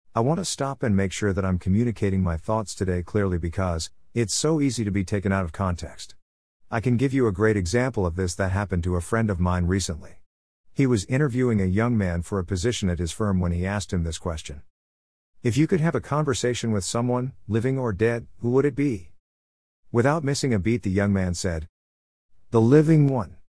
There are two audio versions of the joke — one male voice and one female voice using a more realistic TTS (Talk To Speech) technology in order to provide neutral audio examples as spoken by different genders.
Transformed Joke Example (Male Voice)